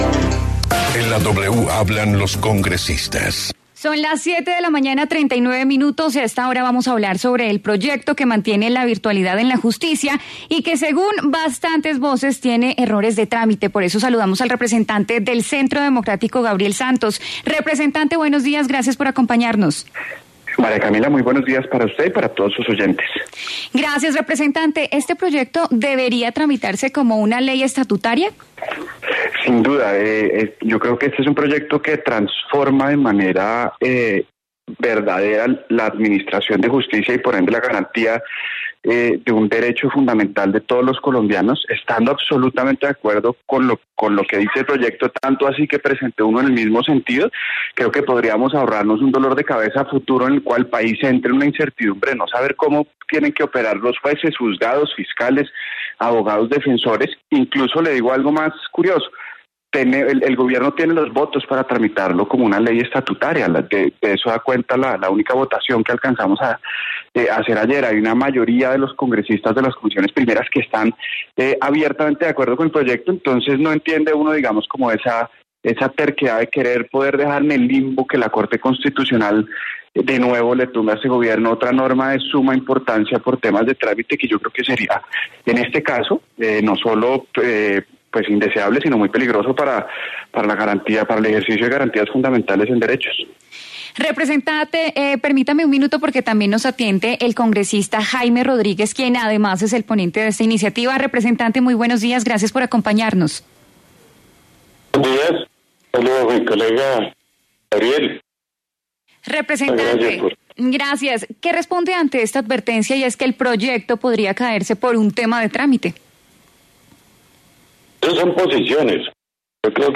En diálogo con La W, los representantes Gabriel Santos y Jaime Rodríguez se refirieron a la iniciativa que se está debatiendo con mensaje de urgencia.